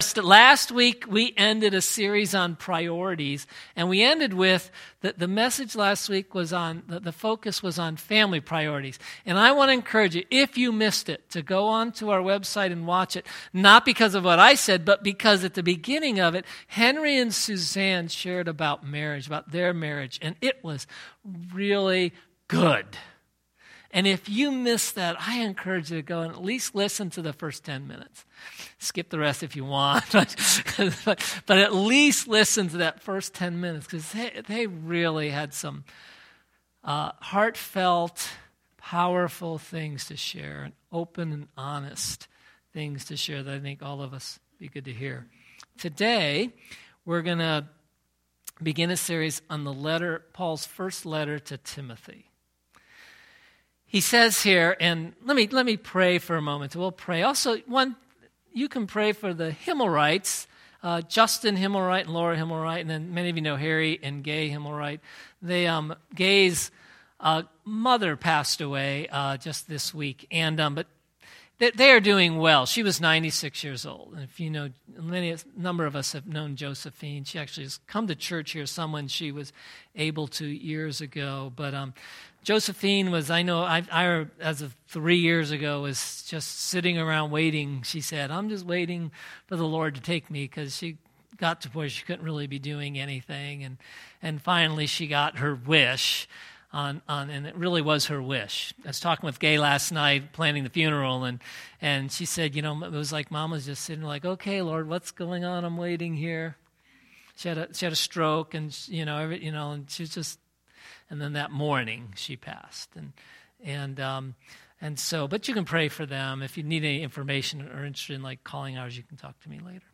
Grace Summit Community Church | Cuyahoga Falls, Ohio